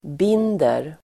Uttal: [b'in:der]